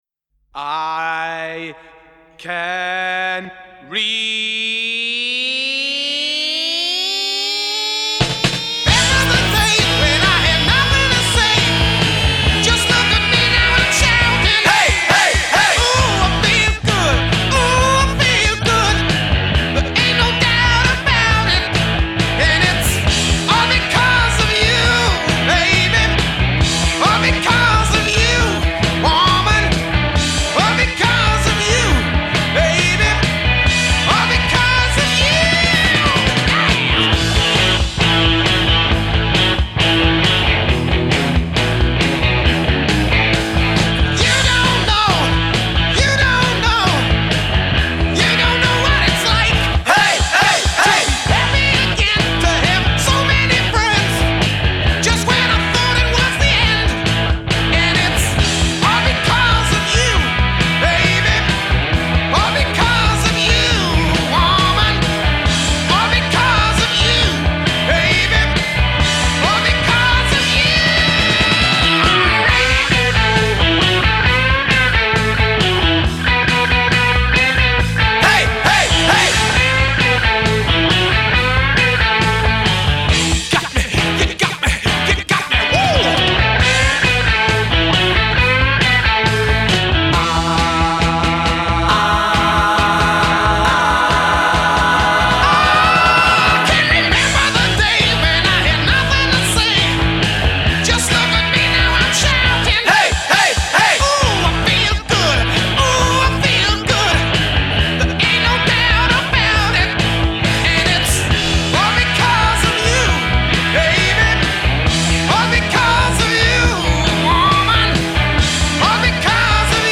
Genre: Rock, Glam Rock